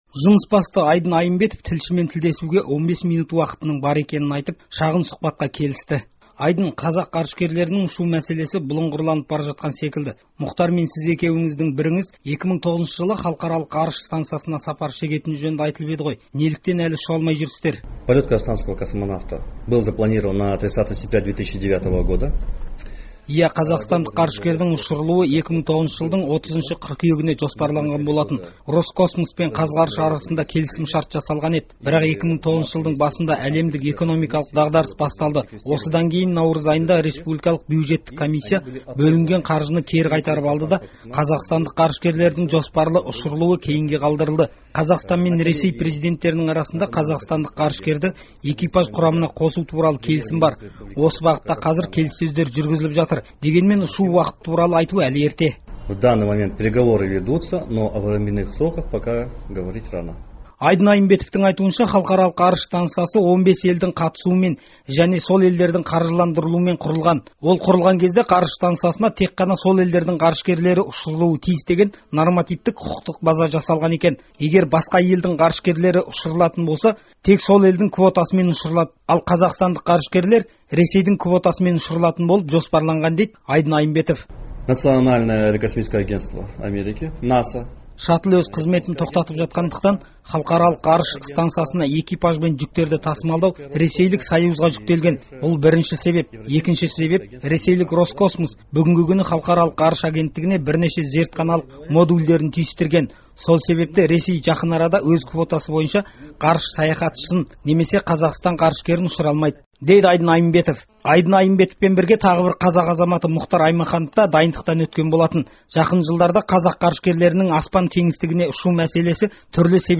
Айдын Айымбетовпен сұқбатты тыңдаңыз